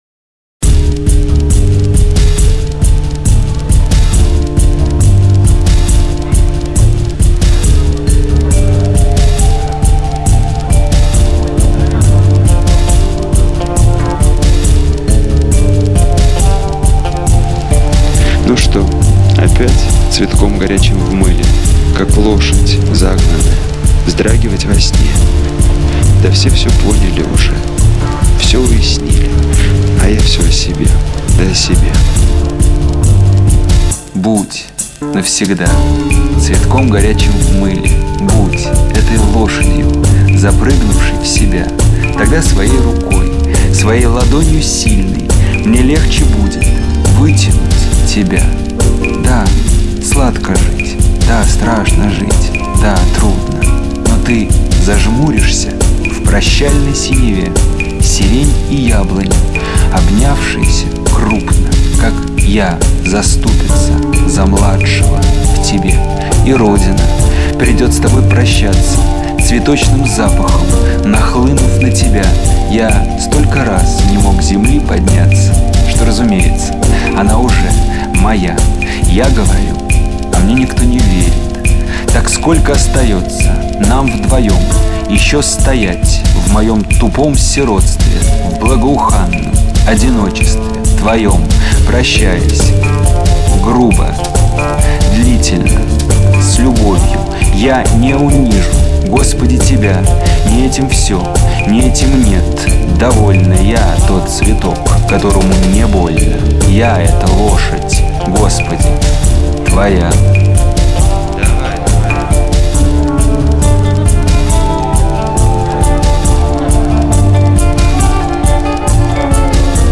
саундтрек к трем книгам